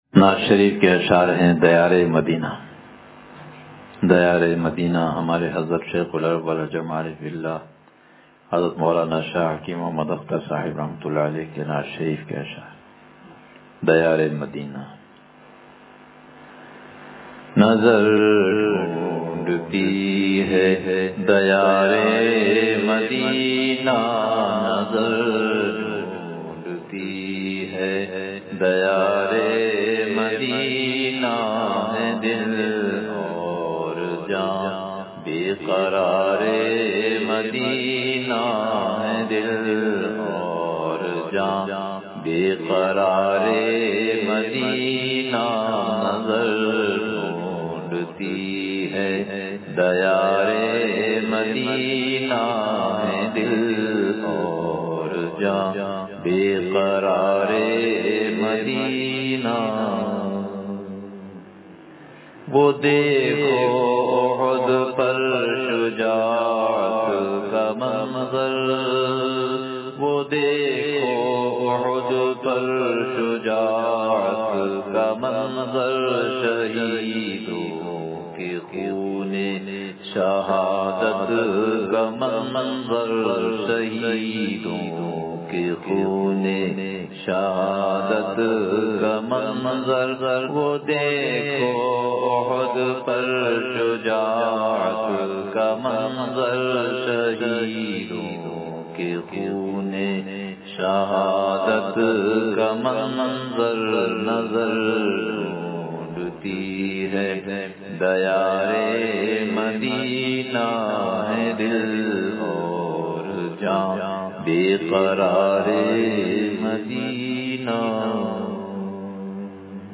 دیار مدینہ – اصلاحی بیان